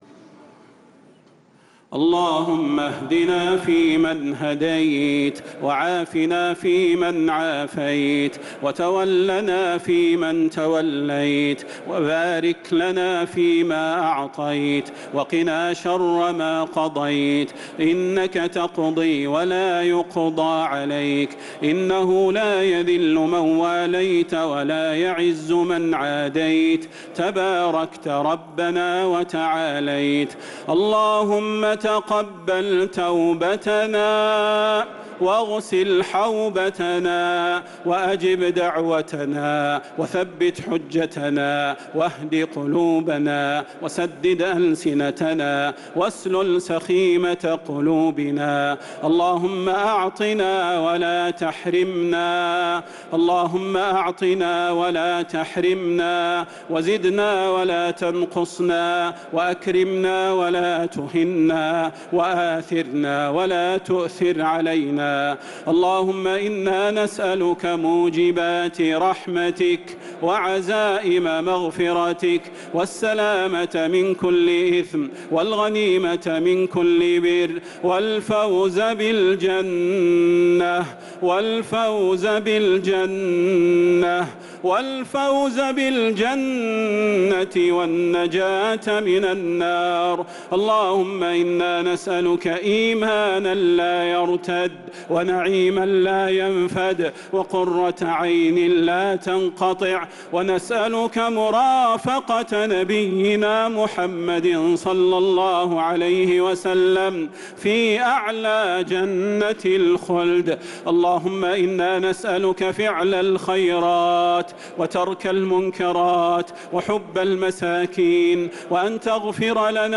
دعاء القنوت ليلة 5 رمضان 1446هـ | Dua 5th night Ramadan 1446H > تراويح الحرم النبوي عام 1446 🕌 > التراويح - تلاوات الحرمين